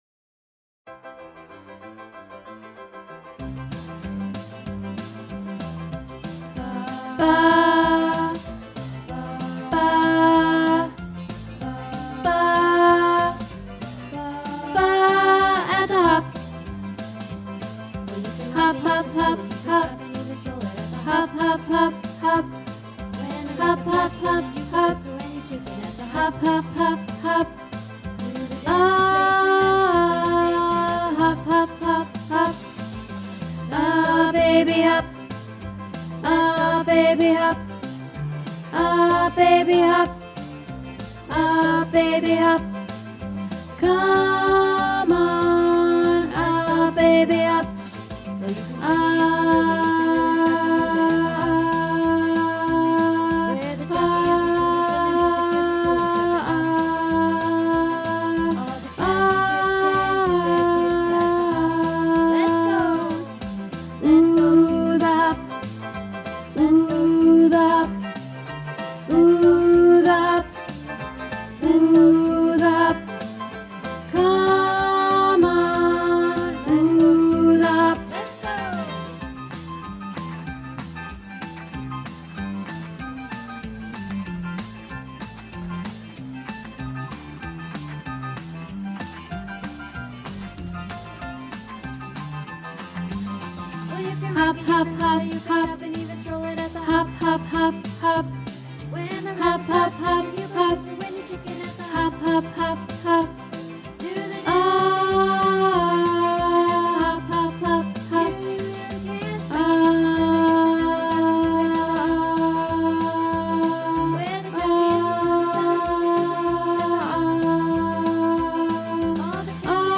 At the Hop - Tenor